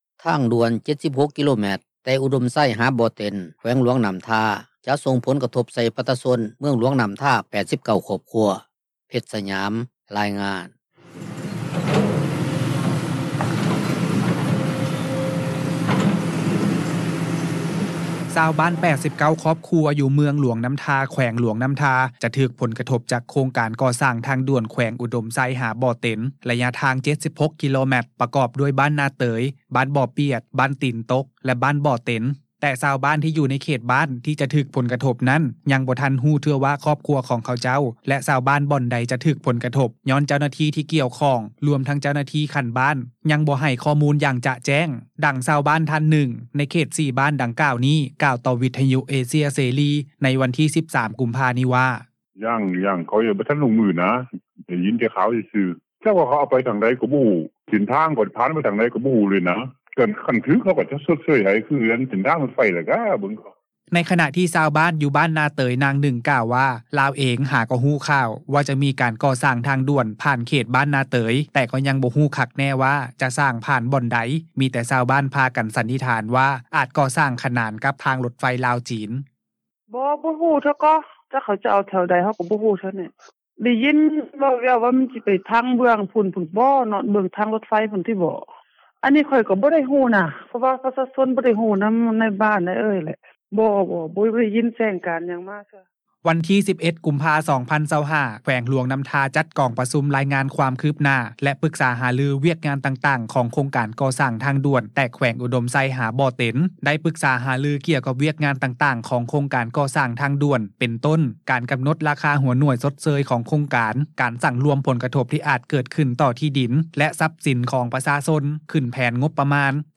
ດັ່ງ ຊາວບ້ານ ທ່ານໜຶ່ງ ໃນເຂດ 4 ບ້ານດັ່ງກ່າວນີ້ ກ່າວຕໍ່ວິທຍຸເອເຊັຽເສຣີ ໃນວັນທີ 13 ກຸມພາ ນີ້ວ່າ:
ໃນຂະນະທີ່ຊາວບ້ານ ຢູ່ເຂດບ້ານນາເຕີຍ ນາງໜຶ່ງ ກ່າວວ່າ ລາວເອງ ຫາກໍຮູ້ຂ່າວ ວ່າຈະມີການກໍ່ສ້າງທາງດ່ວນ ຜ່ານເຂດບ້ານນາເຕີຍ ແຕ່ກໍຍັງບໍ່ຮູ້ຄັກແນ່ວ່າ ຈະສ້າງຜ່ານບ່ອນໃດ, ມີແຕ່ຊາວບ້ານພາກັນສັນນິຖານວ່າ ອາດກໍ່ສ້າງ ຂະໜາດກັບເສັ້ນທາງ ລົດໄຟລາວ-ຈີນ.